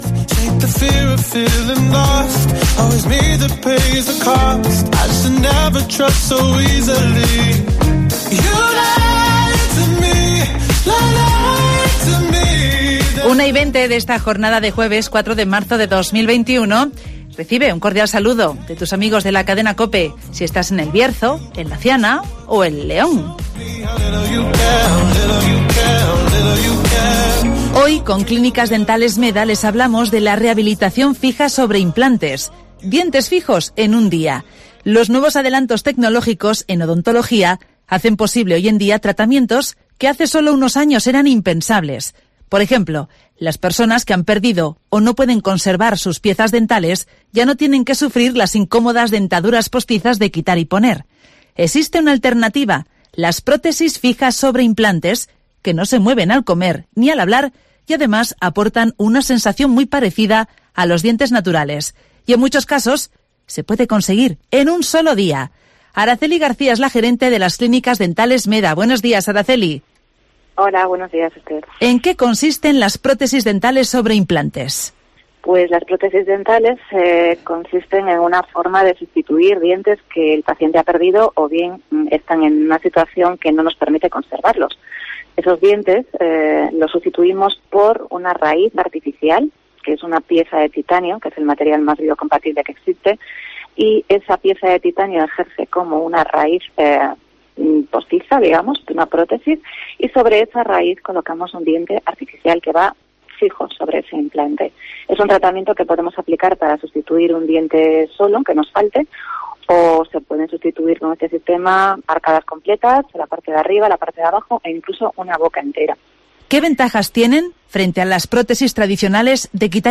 Cambia la dentadura postiza por prótesis fijas sobre implantes en las clínicas dentales Meda (Entrevista